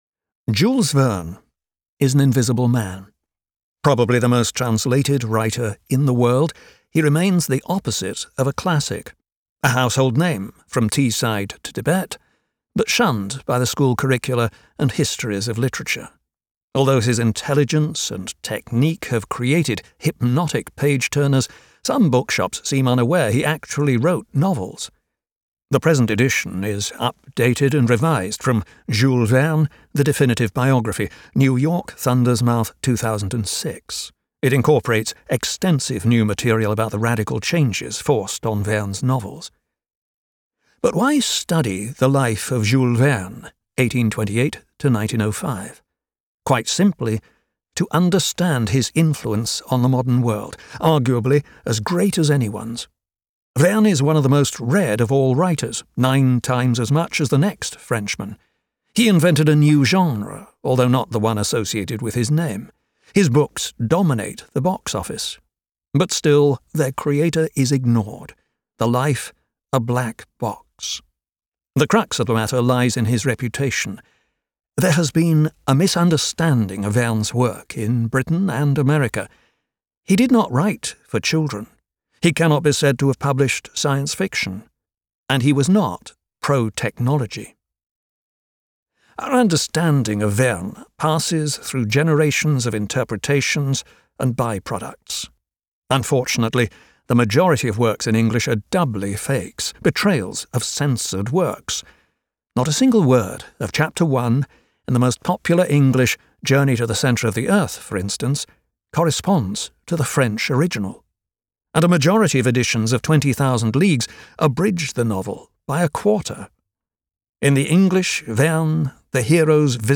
For this audio edition, the text has been fully revised and contains much new material.